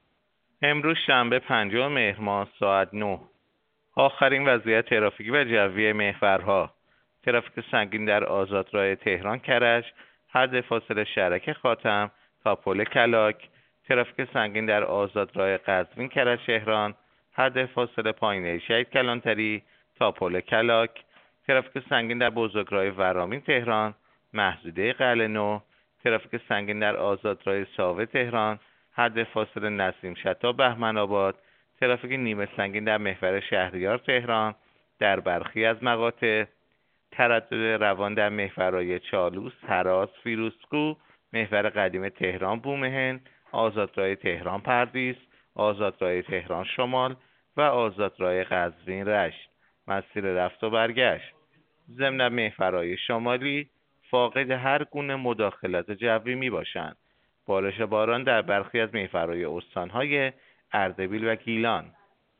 گزارش رادیو اینترنتی از آخرین وضعیت ترافیکی جاده‌ها ساعت ۹ پنجم مهر؛